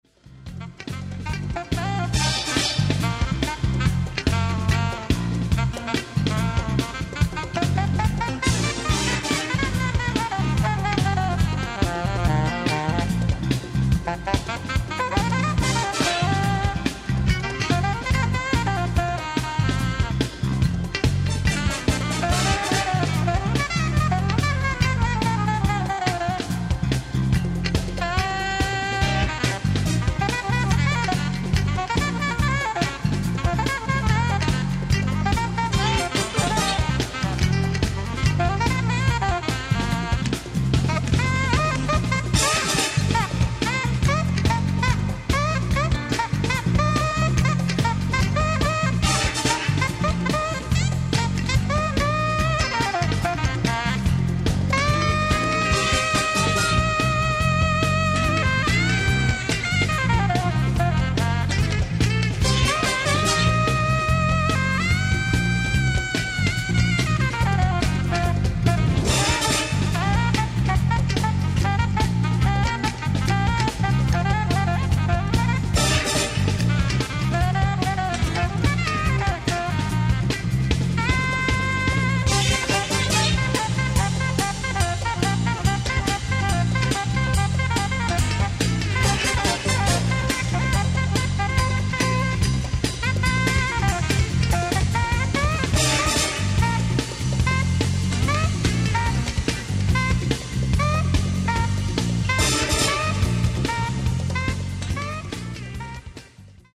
ライブ・アット・ポール・ヴィデル劇場、ミラマ、フランス 02/16/1988
※試聴用に実際より音質を落としています。